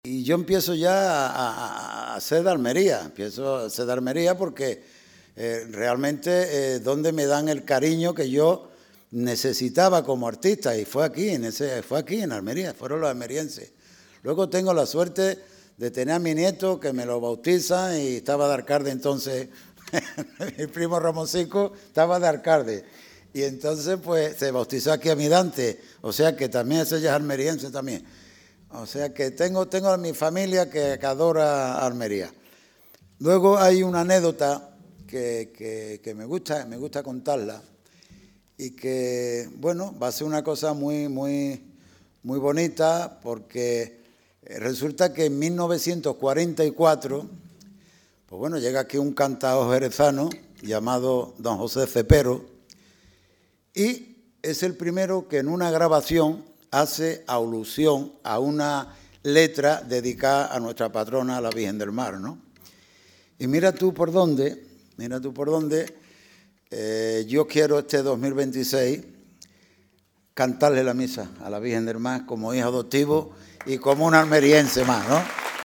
José Mercé ya es un almeriense más tras recoger el título de ‘Hijo Adoptivo’ de la ciudad en un repleto Teatro Apolo
“Ser nombrado hijo adoptivo de Almería, ser almeriense, es algo que recibo con la mayor satisfacción y orgullo porque siempre he sido almeriense”, ha compartido un José Mercé muy emocionado, sonriente y agradecido.